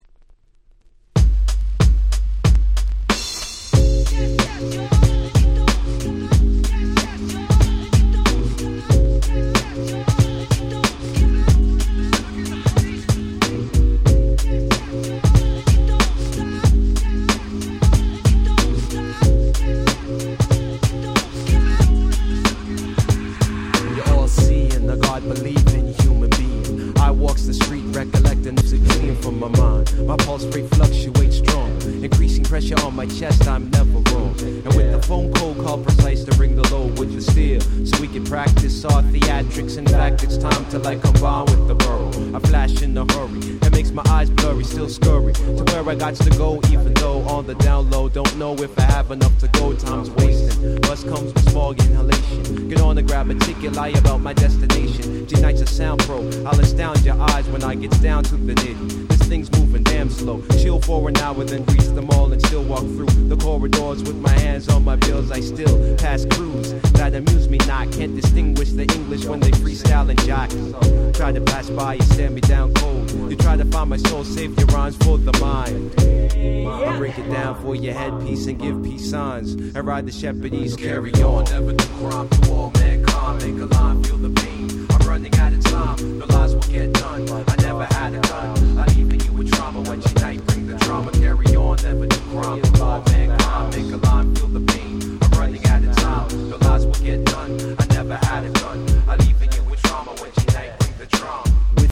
95' Super Nice Canadian Underground Hip Hop !!